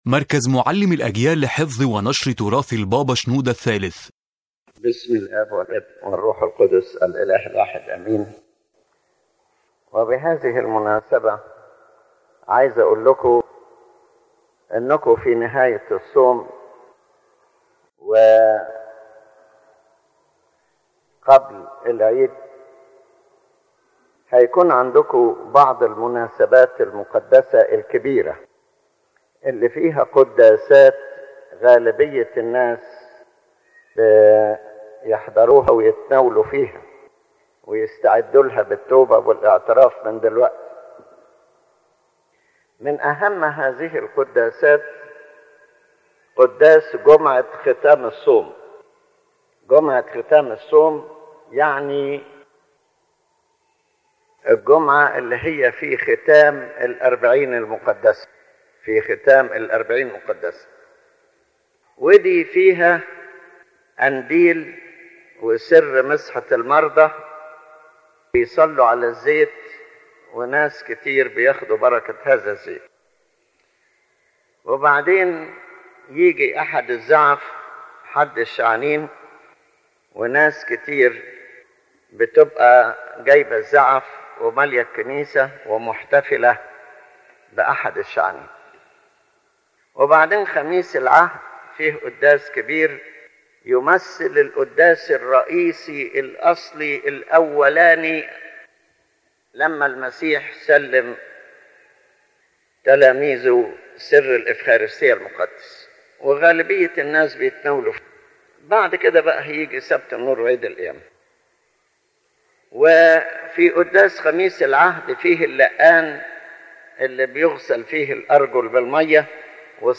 General Introduction In this lecture, His Holiness Pope Shenouda III explains the rituals of the final days of Great Lent and their spiritual meanings, focusing on three main liturgical signs used in the church: the water of the laver, the oil of the anointing sacrament, and the palm fronds and olive branches on Palm Sunday.